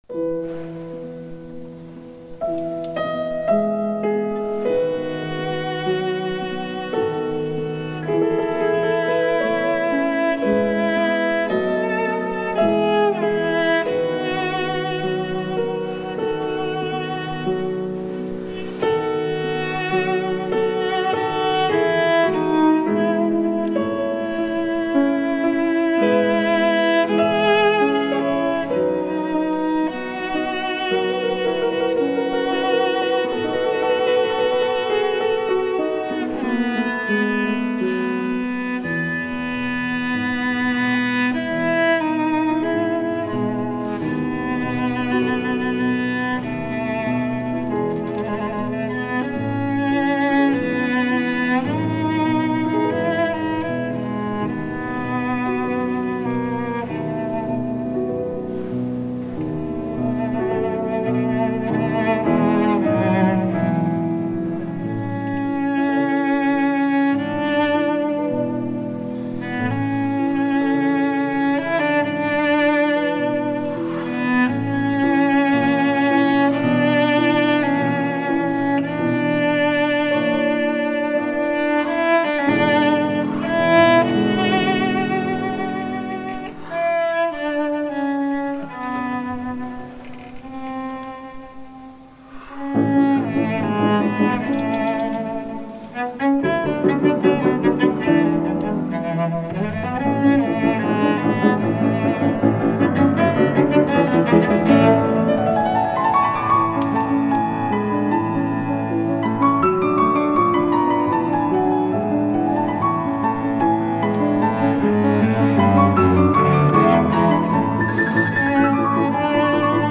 Teatro Comunale
Quartetto d’Archi
Pianoforte